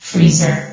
freezer.ogg